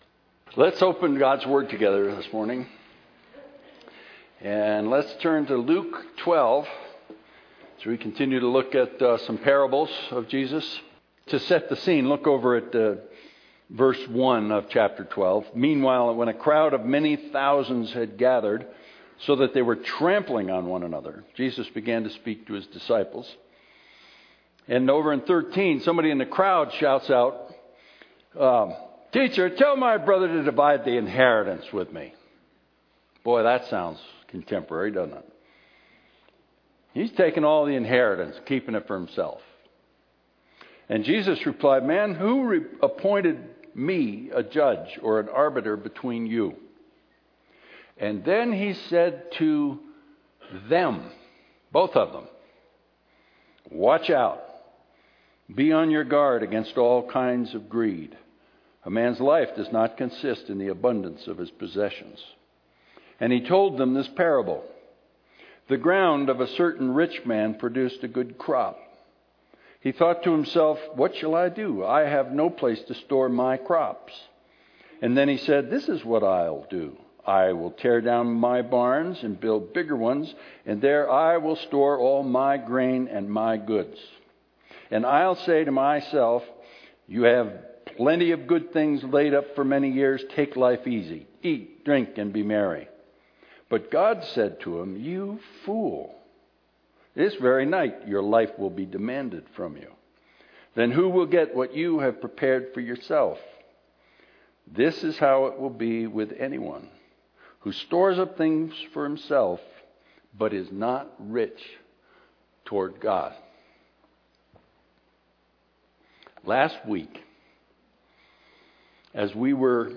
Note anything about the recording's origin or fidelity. • When you hear me say “Remember this guy?” I’m referring to a picture I originally projected.